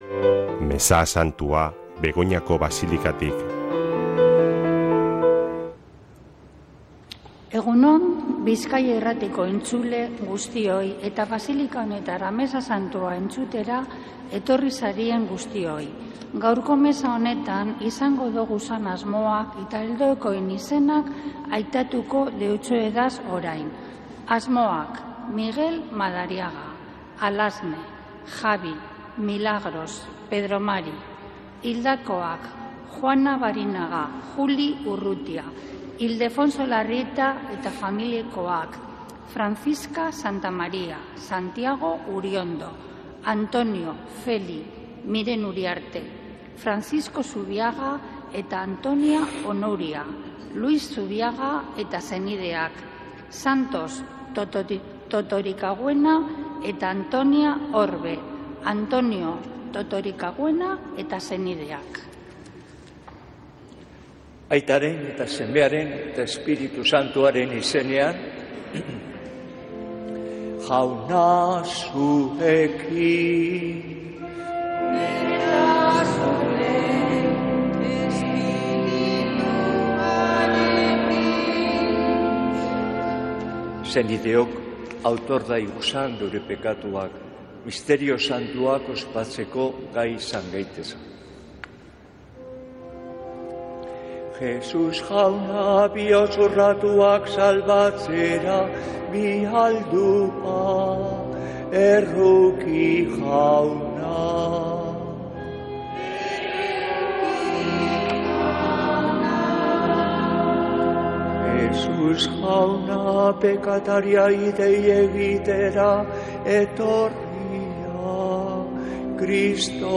Mezea (24-12-16)